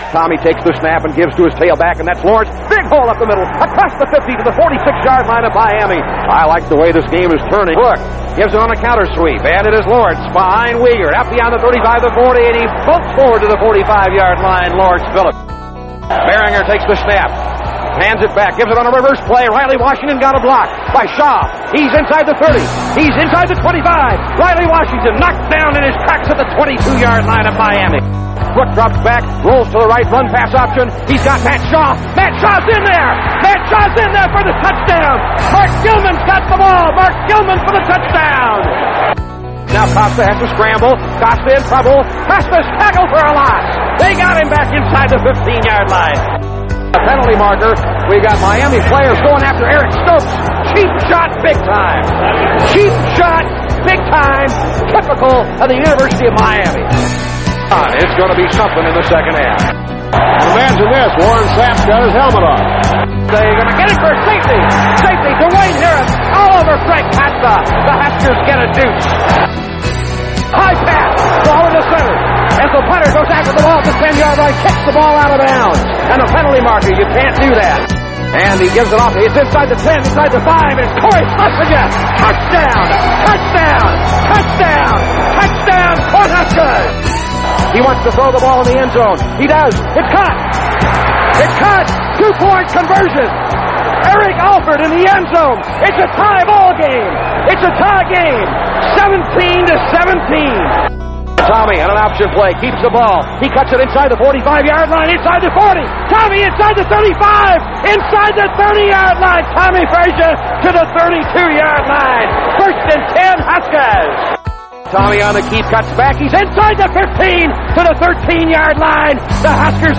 RADIO HIGHLIGHTS